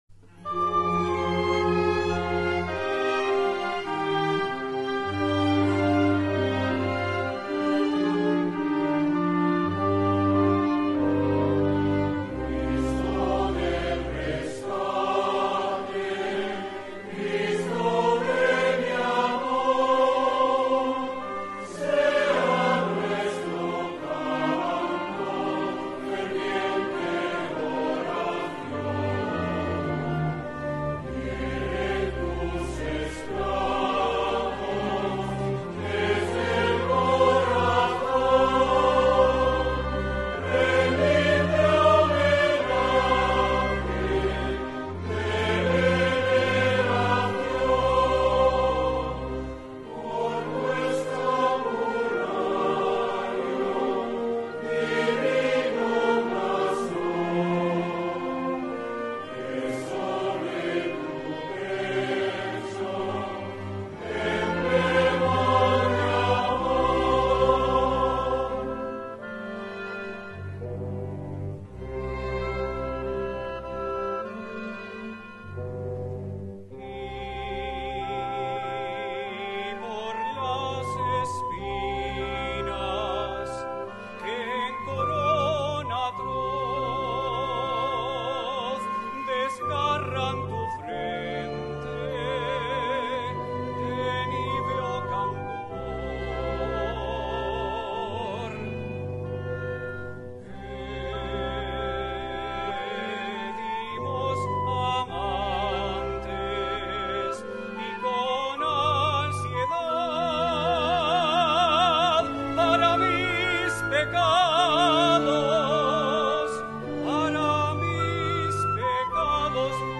Himno Ntro. Padre Jesús del Rescate